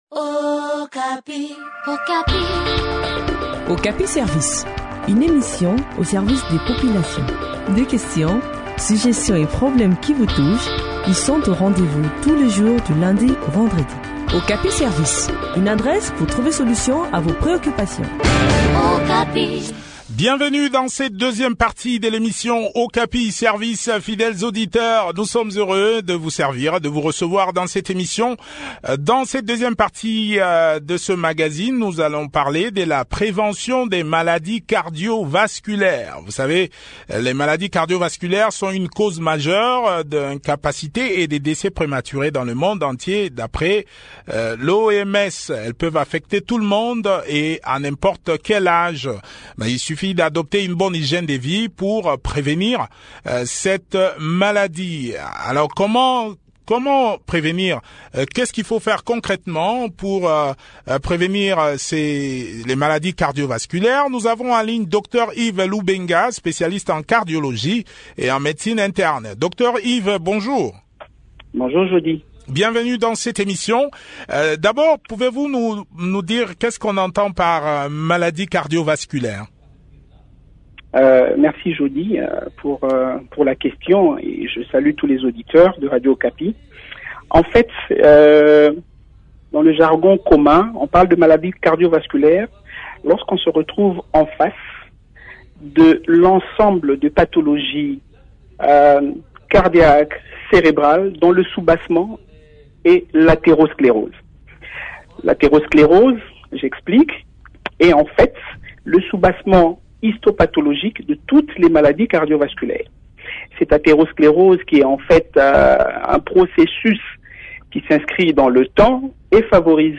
expert en médecine interne et en cardiologie.